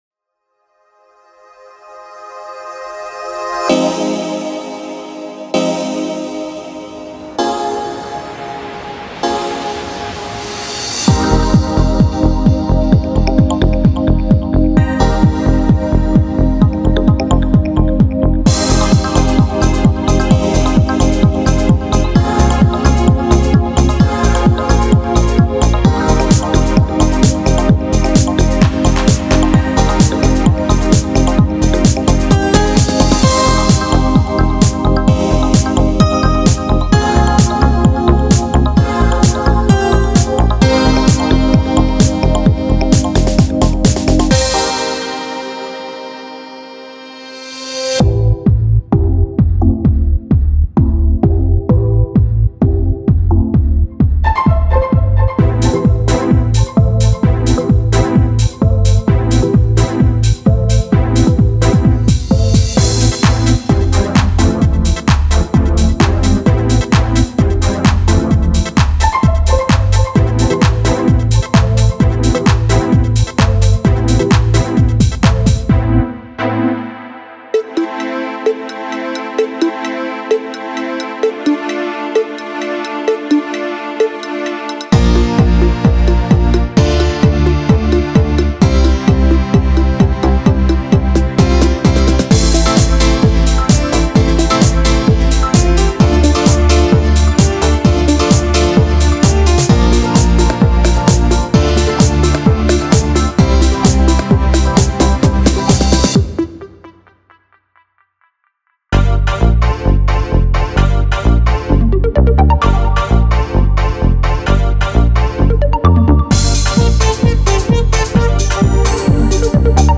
Genre:Classic House
80年代後半から90年代初頭にかけてのクラシックハウスとUKハウスを融合させた内容です。
デモサウンドはコチラ↓